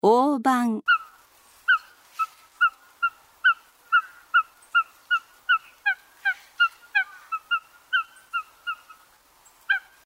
オオバン
【鳴き声】普段はあまり鳴かず、繁殖期に「ケッ」「キュッ」「ピッ」などと鳴く。
オオバンの鳴き声（音楽：166KB）